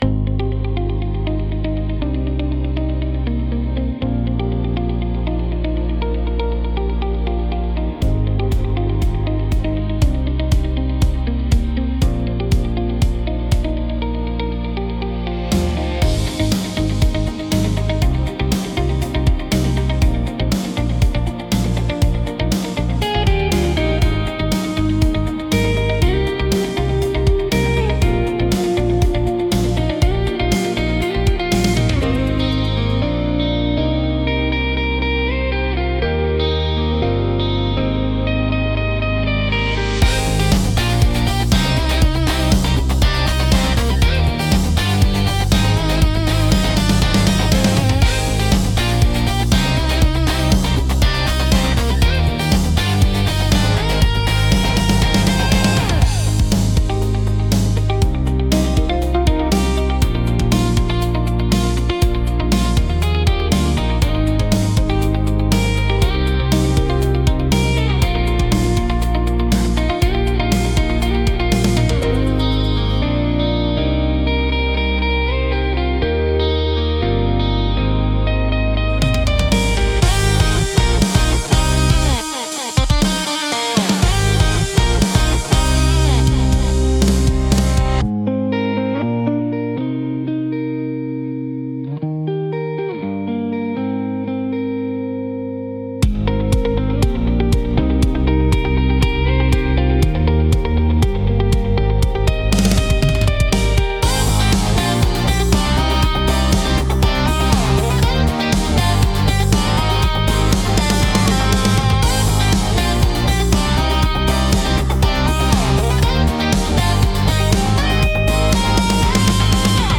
コーポレートは、ミュートギターを主体にした穏やかで落ち着いた曲調が特徴です。
シンプルで洗練されたアレンジが安心感を与え、クリーンでプロフェッショナルな印象を作り出します。